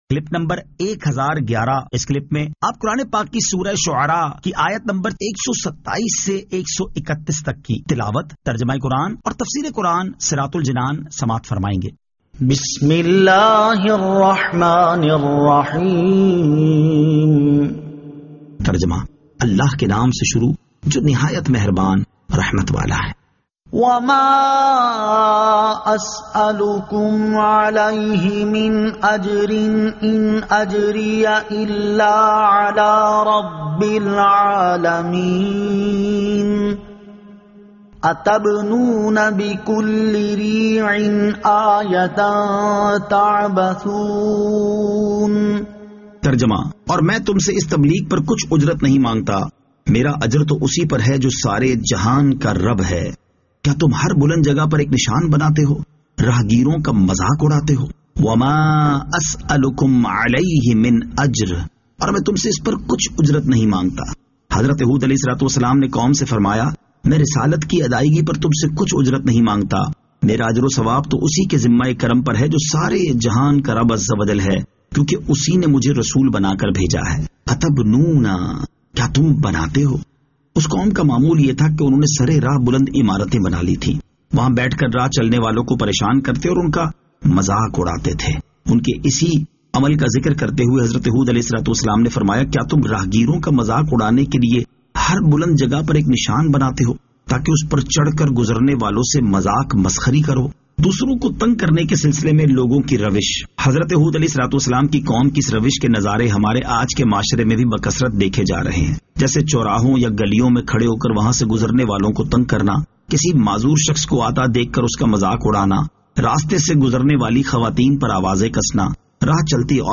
Surah Ash-Shu'ara 127 To 131 Tilawat , Tarjama , Tafseer